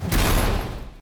rocket sounds
fire2.ogg